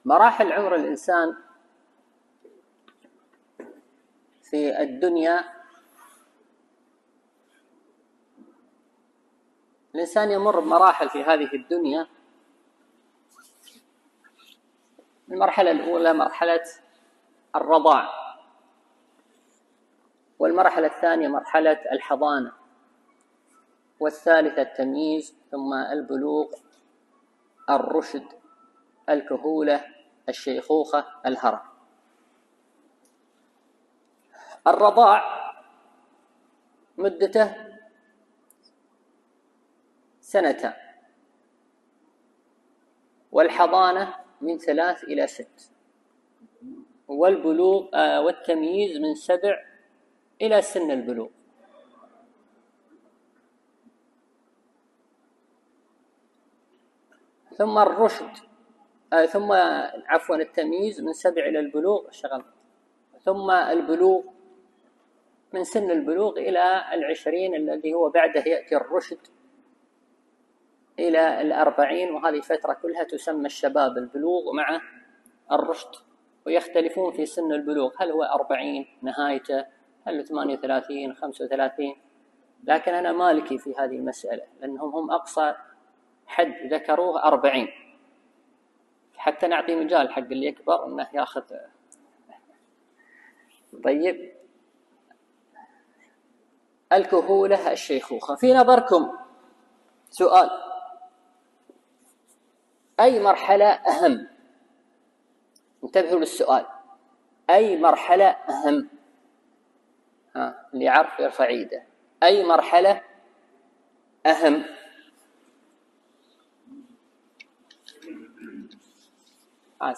التنسيق: MP3 Mono 44kHz 67Kbps (VBR)